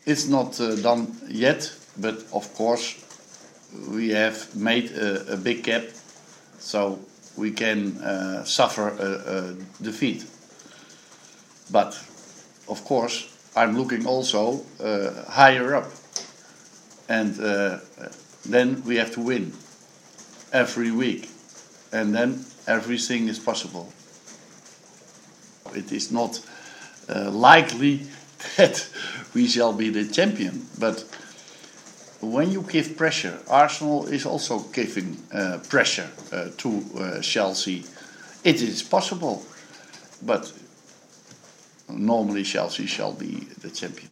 Listen to United manager Louis van Gaal saying he is expecting Chelsea to win the Premier League this season.